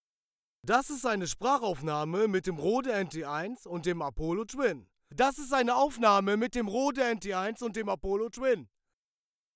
Knacksen in Aufnahme At 4047 + Focusrite Scarlett 2i2
Bei bestimmten Stellen vor allem bei lauteren knackt es bzw. so Knall Geräusche.
Meine Aufnahme Kette besteht aus dem Rode Nt1 - Popfilter und einem Apollo Twin Usb.
Habe eine Aufnahme mit und ohne Compressor.